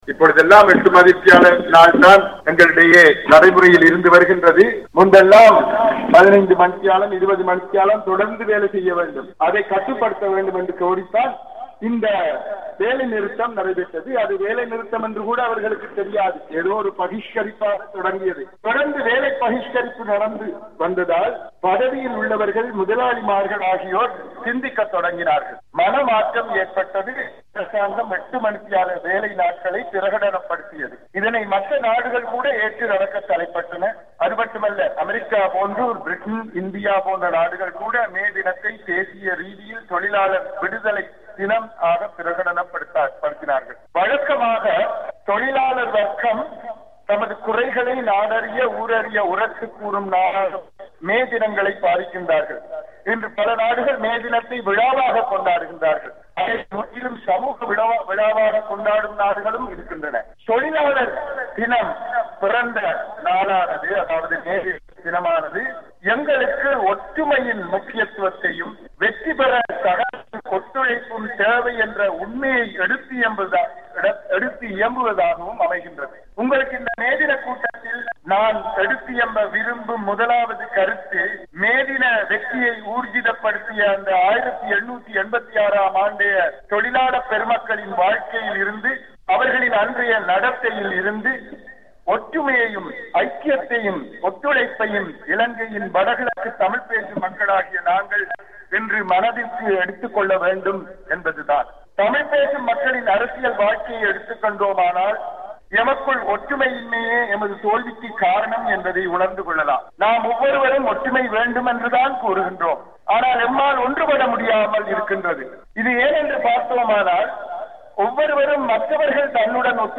இலங்கையின் வடமாகாண முதலமைச்சர் சி வி விக்னேஸ்வரன் மேதின கூட்டத்தில் ஆற்றிய உரையில், தமிழ்த்தேசிய கூட்டமைப்பிற்குள் இருக்கும் கட்சிகளில் சிலரே சுயநலத்துடன் செயற்படுவதாகவும், அதனால், தமிழர்களின் நன்மைகள் பின்னுக்குத்தள்ளப்படுவதாகவும் விமர்சித்திருக்கிறார்.